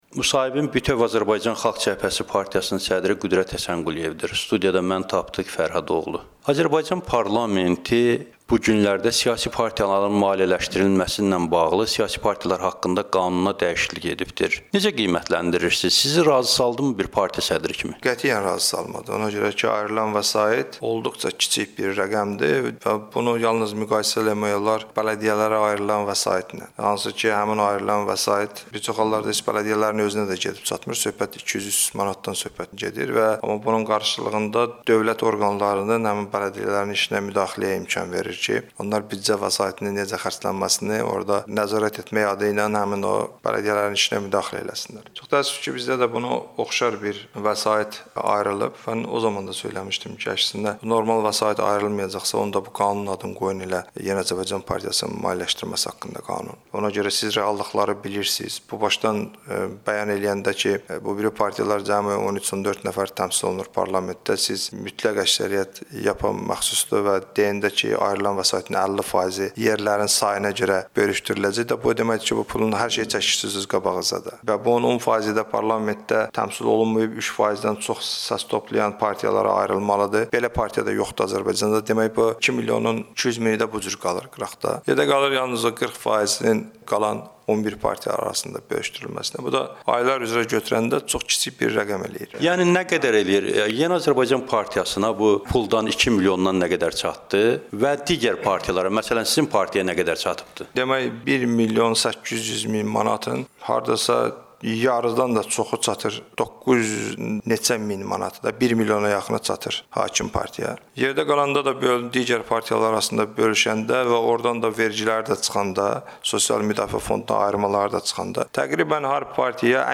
BAXCP sədri Qüdrət Həsənquliyevlə müsahibə